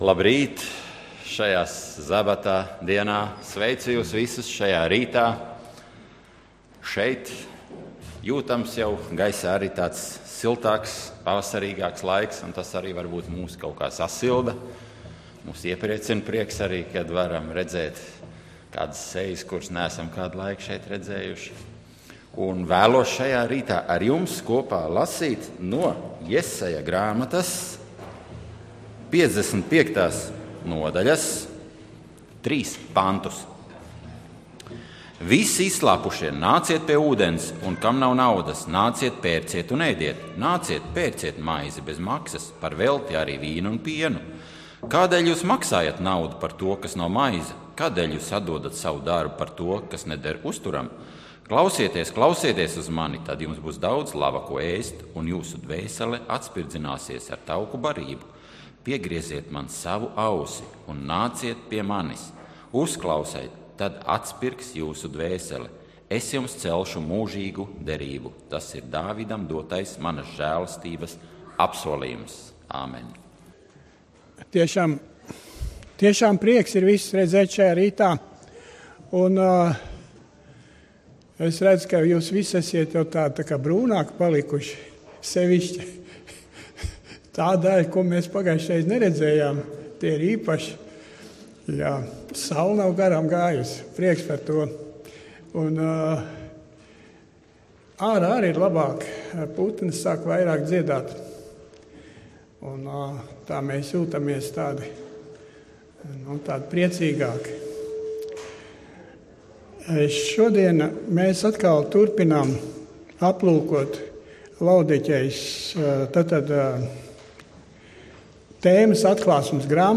(Jes 55: 1-3) Dievkalpojums 28.03.2015: Klausīties
Svētrunas